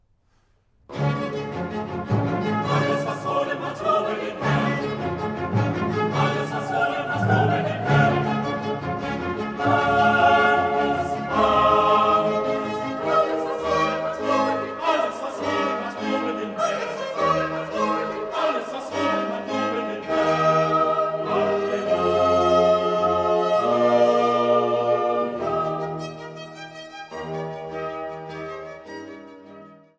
Sopran
Alt
Tenor
Bass
Silbermann-Orgel des Freiberger Domes